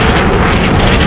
rocket_engine.ogg